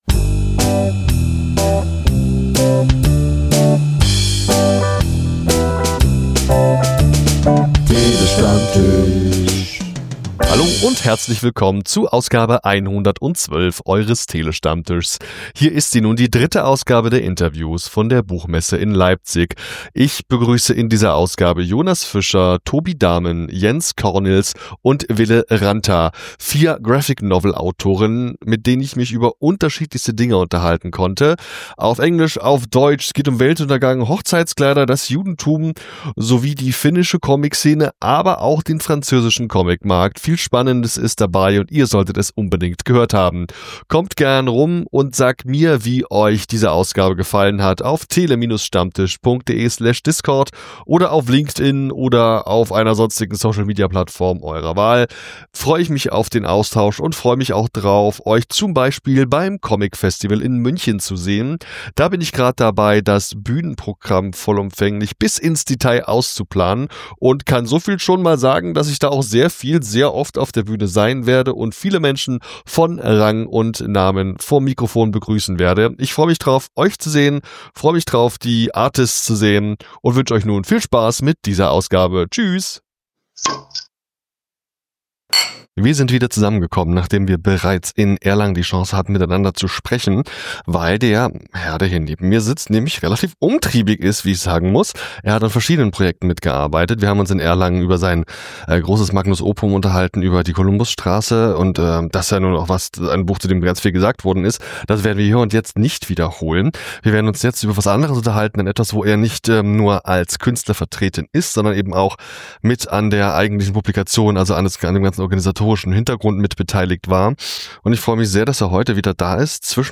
In Folge 3 meines Messe-Specials von der Leipziger Buchmesse und Manga-Comic-Con 2025 habe ich wieder vier spannende Gäste und Themen für dich dabei.